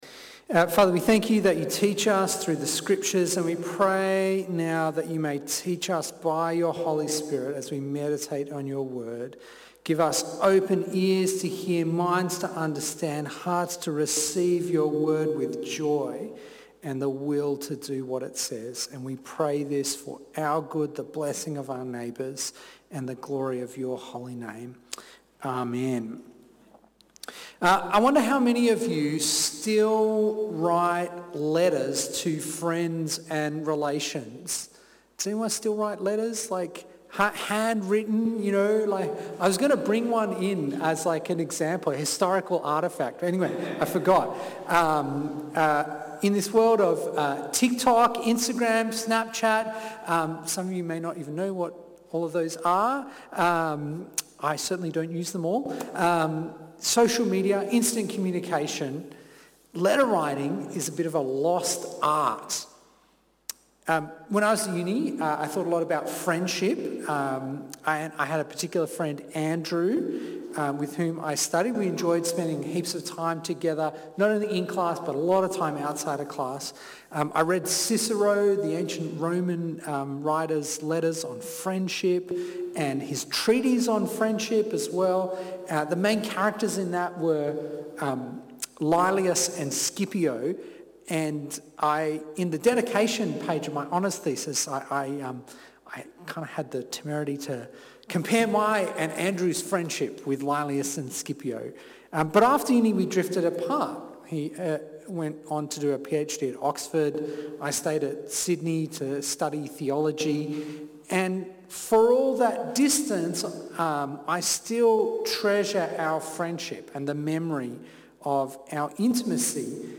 Partners in Christ Preacher